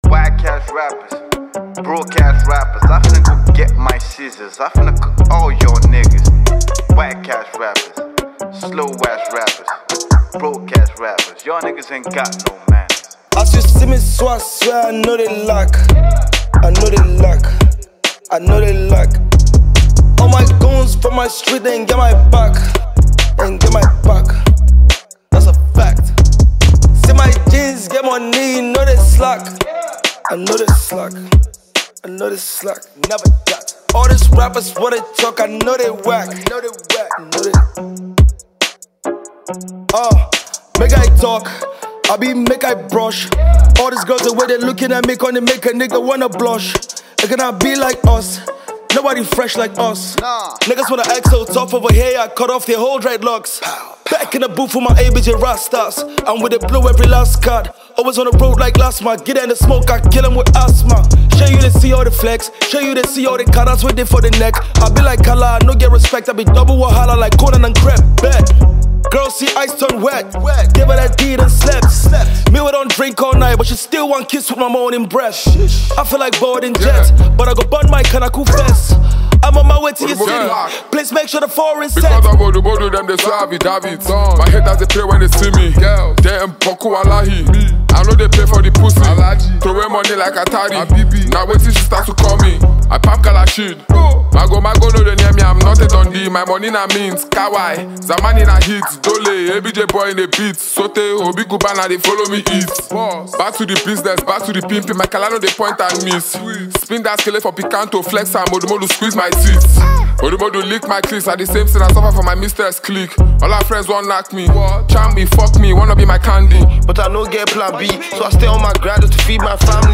it’s a trap.